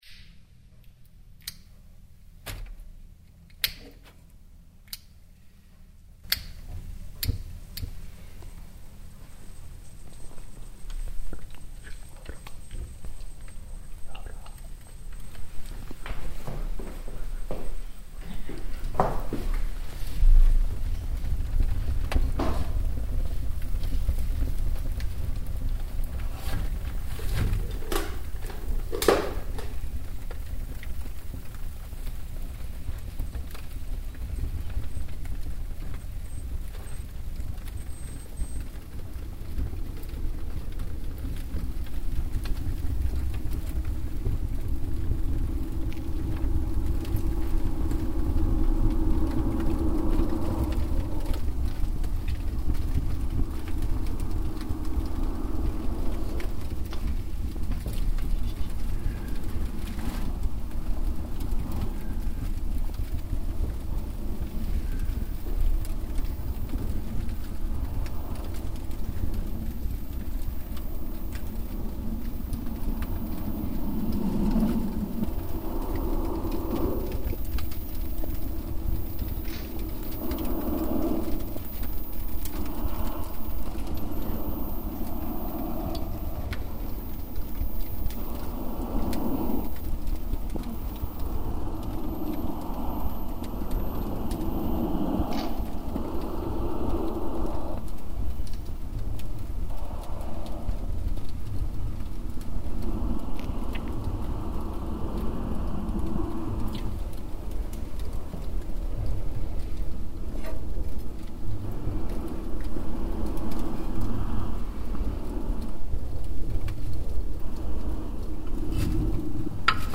File under: Electroacoustic / Experimental / Ambient
le ritrose rarefazioni